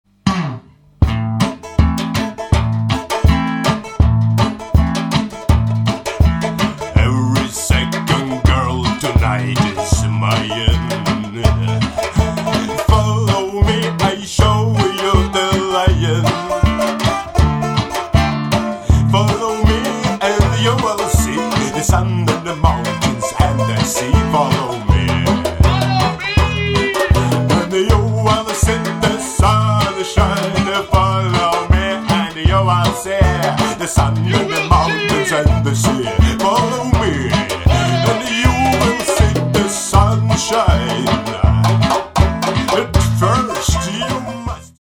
Make a drum set out of Djembe, Darabuka and Talking Drums.